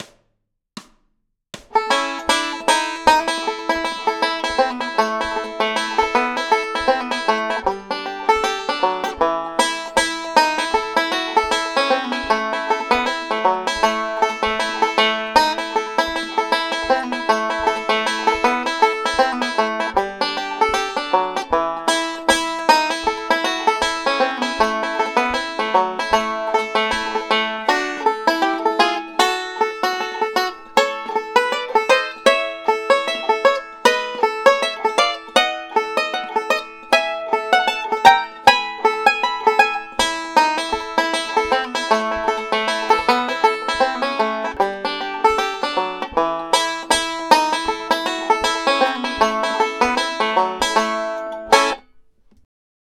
my very straight ahead version of a banjo tune